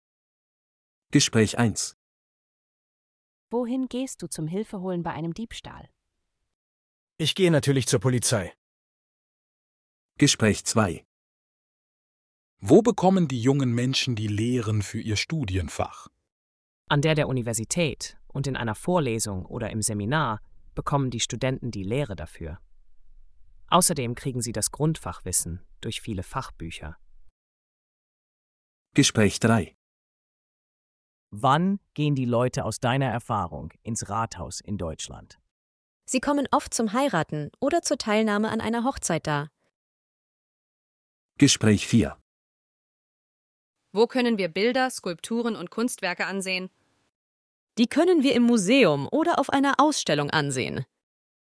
Bài nghe dành cho các cuộc hội thoại trong bài tập số 4 này: